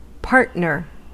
Ääntäminen
US : IPA : [ˈpɑɹt.nɚ]